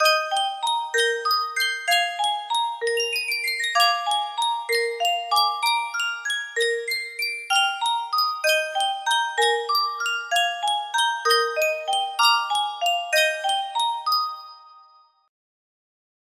Sankyo Music Box - The Skaters' Waltz HG music box melody
Full range 60